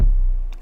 Layer Kick (Power).wav